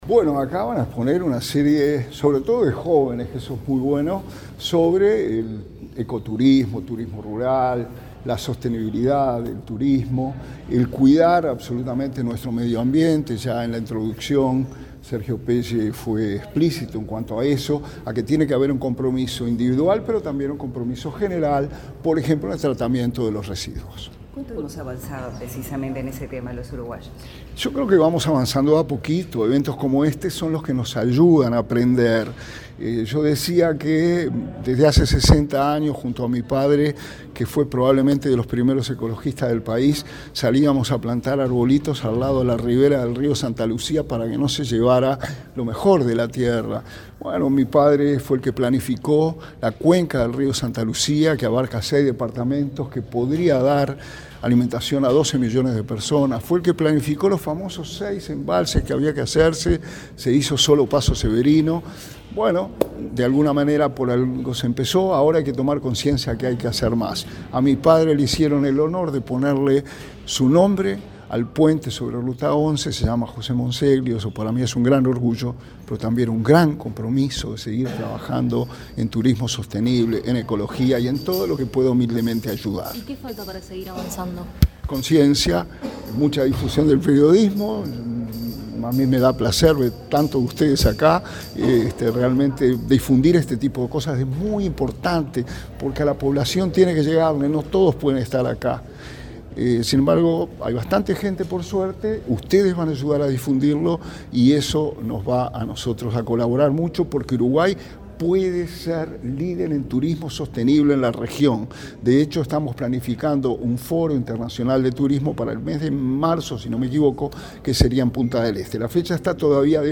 Declaraciones del subsecretario de Turismo, Remo Monzeglio
Declaraciones del subsecretario de Turismo, Remo Monzeglio 24/07/2023 Compartir Facebook X Copiar enlace WhatsApp LinkedIn El subsecretario de Turismo, Remo Monzeglio, dialogó con la prensa luego de participar, en Montevideo, en la presentación de la primera edición del foro América Verde.